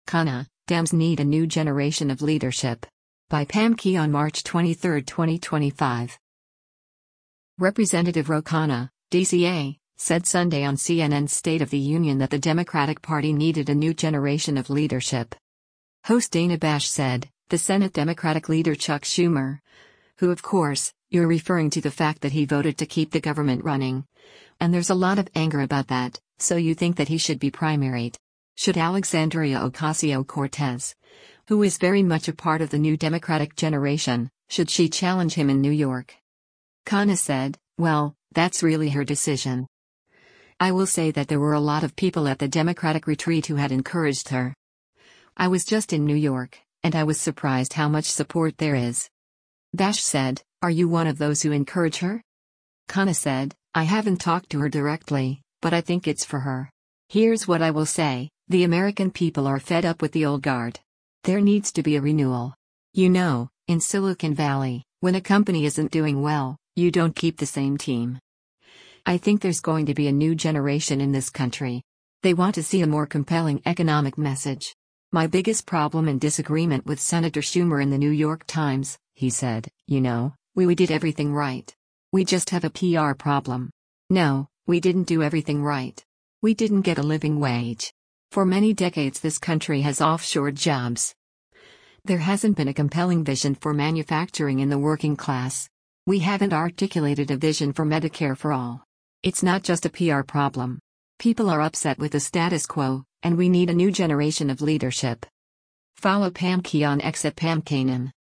Representative Ro Khanna (D-CA) said Sunday on CNN’s  “State of the Union” that the Democratic Party needed a “new generation of leadership.”